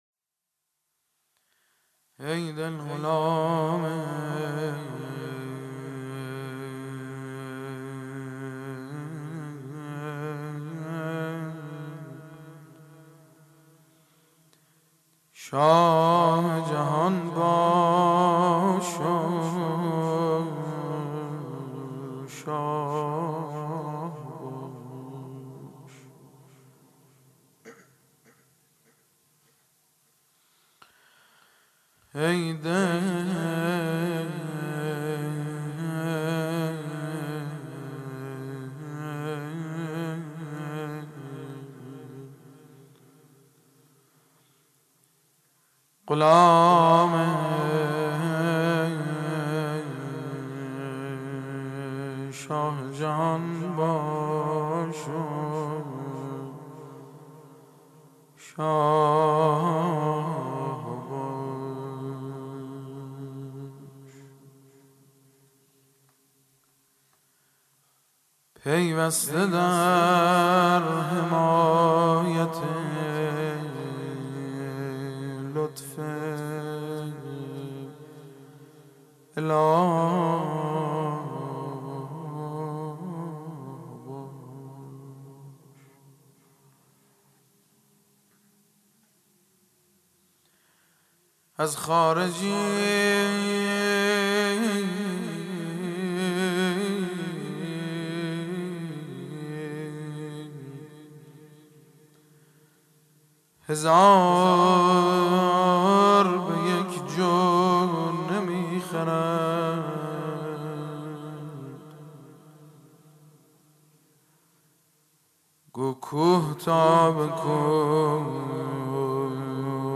01-Rozeh.mp3